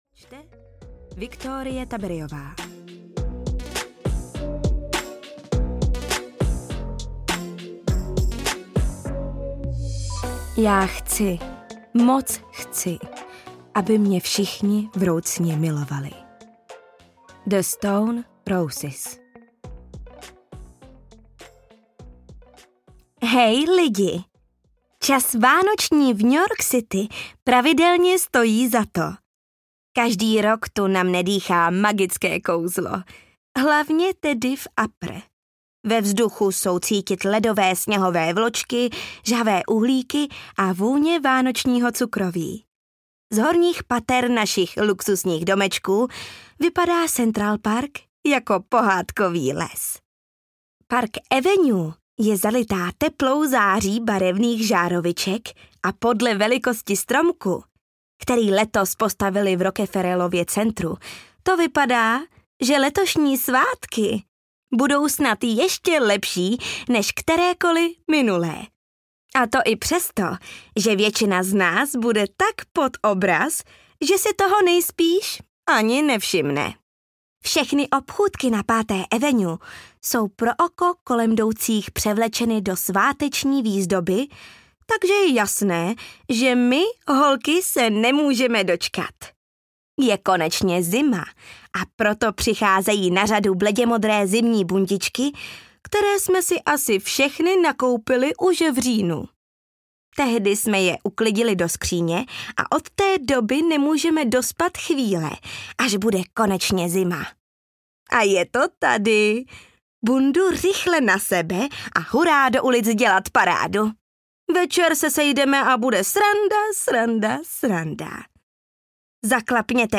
Gossip Girl: Nechci víc než všechno audiokniha
Ukázka z knihy